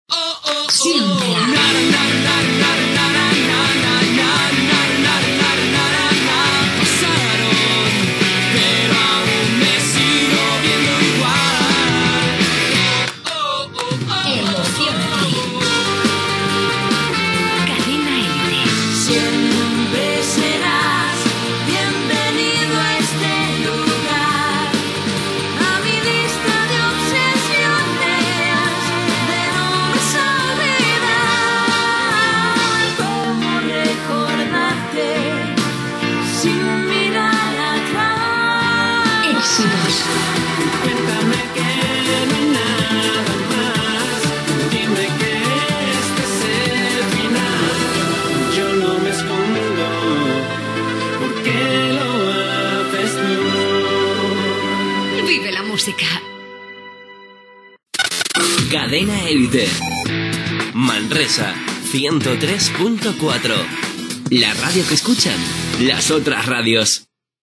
Indicatiu de la cadena i de l'emissora a Manresa.
FM